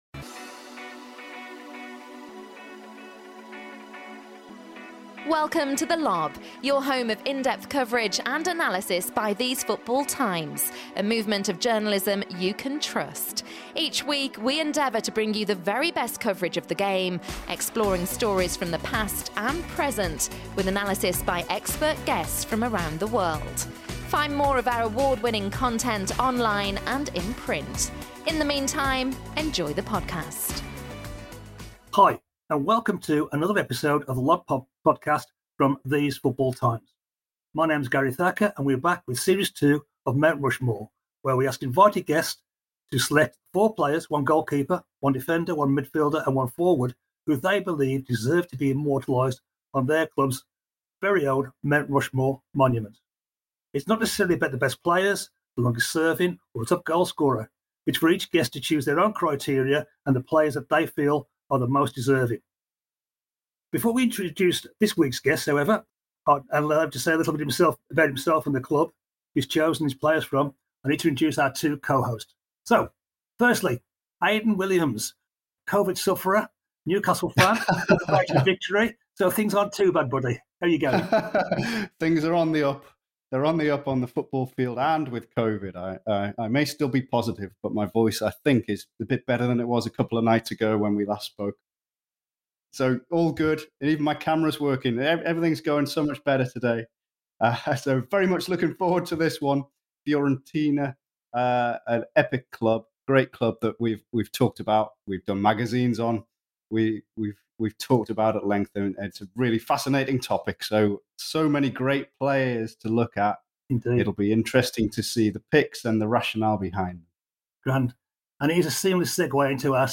There’s plenty of discussion and debate as we both laud his choices and advocate others who could have been included.